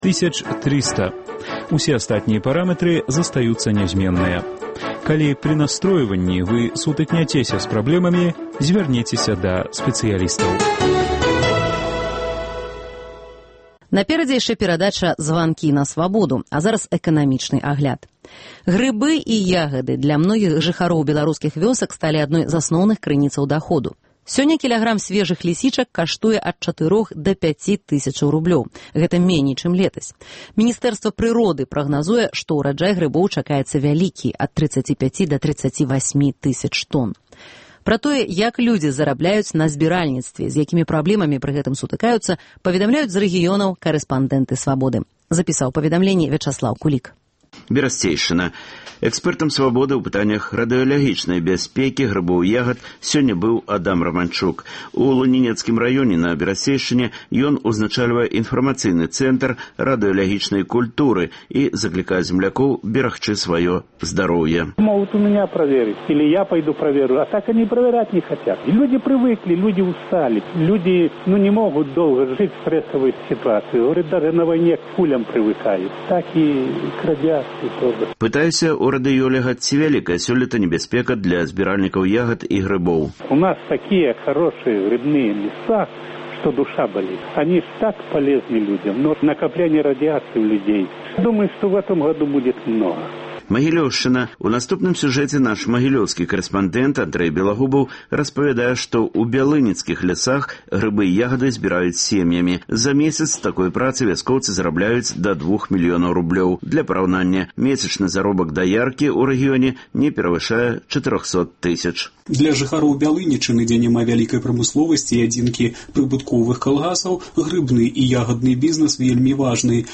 Гутаркі без цэнзуры зь дзеячамі культуры й навукі